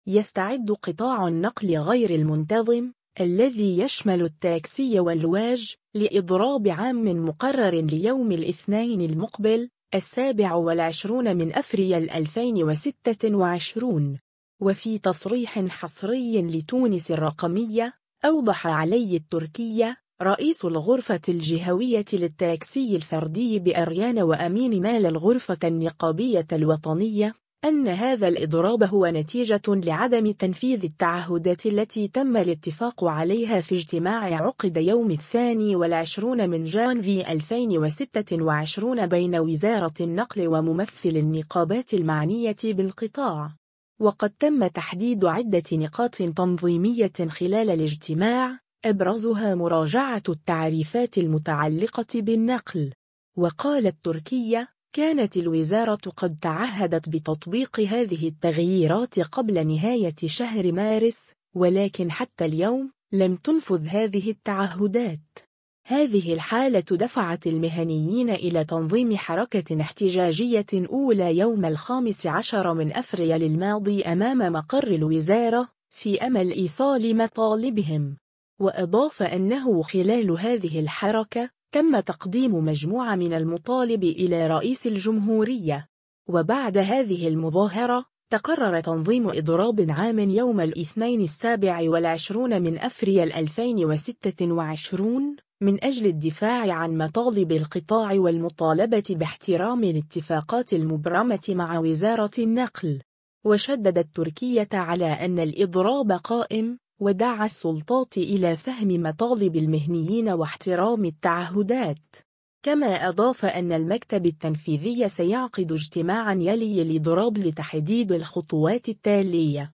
وفي تصريح حصري